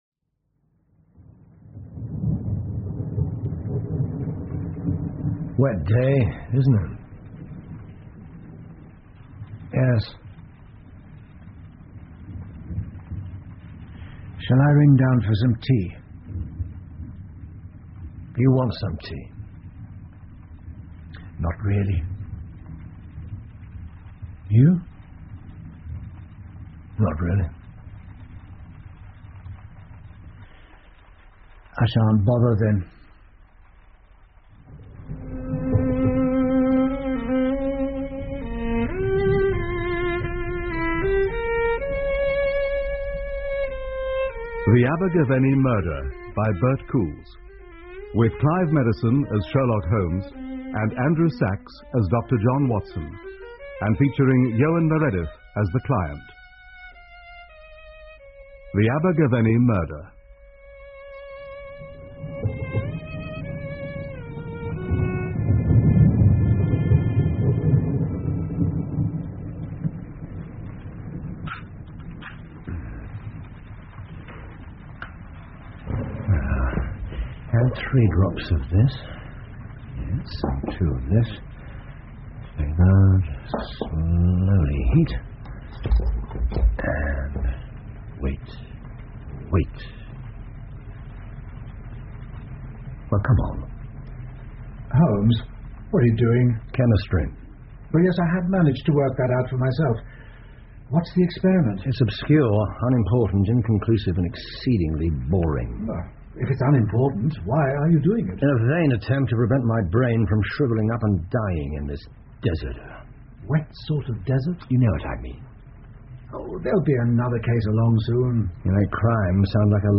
福尔摩斯广播剧 The Abergavenny Murder 1 听力文件下载—在线英语听力室